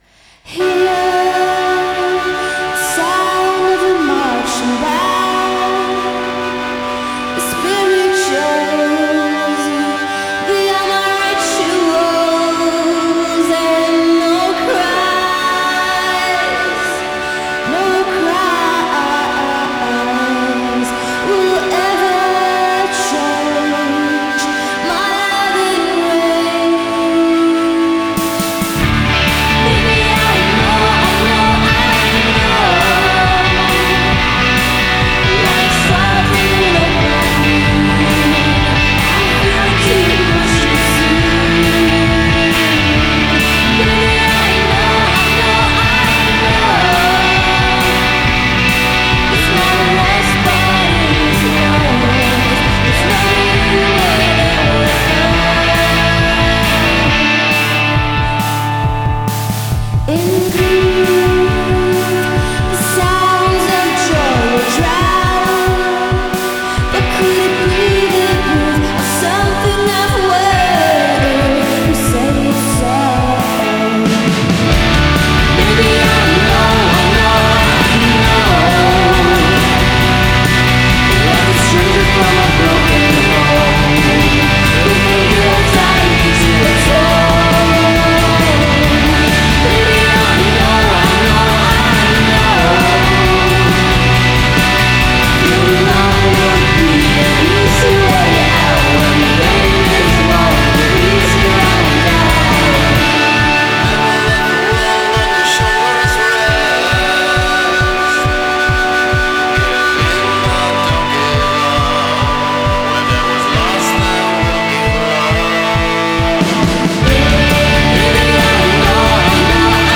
just quality JAMC-inspired jangle noise.